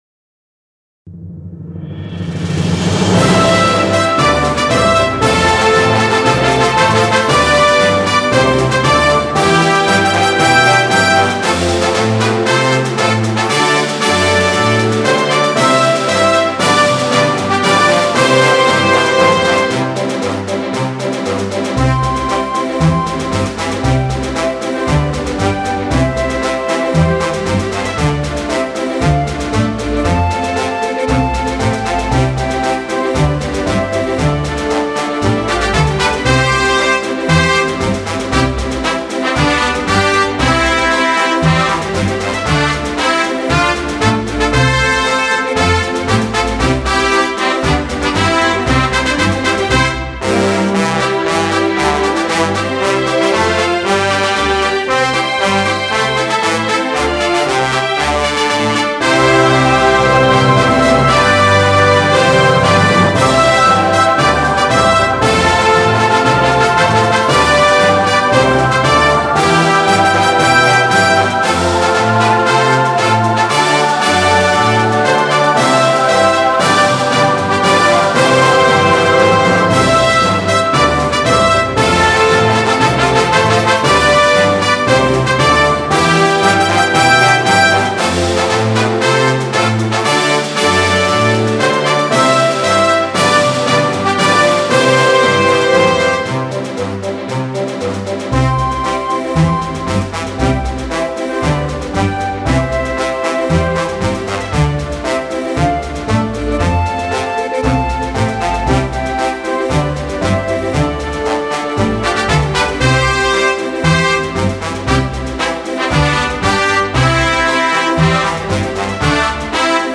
泰安长城中学校歌（伴奏）：